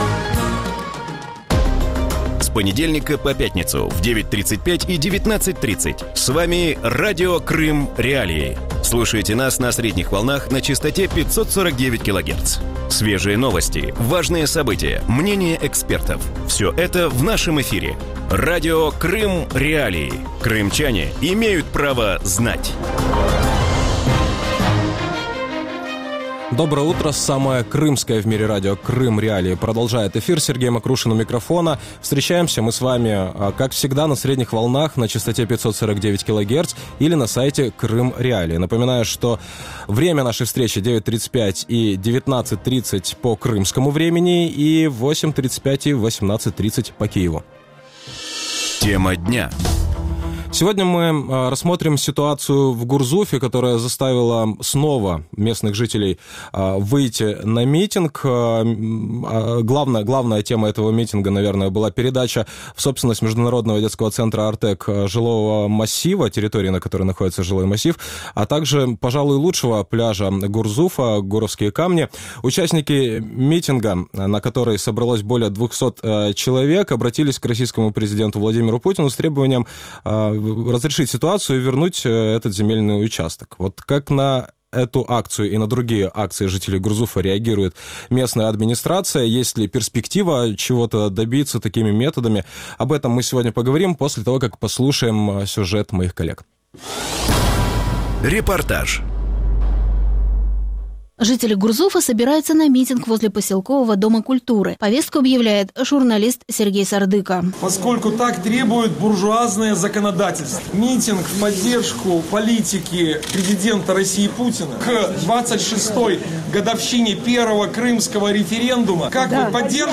В утреннем эфире Радио Крым.Реалии говорят о протесте в Гурзуфе. Местные жители вышли на митинг против передачи жилого массива, а также пляжа «Гуровские камни» международному детскому центру «Артек».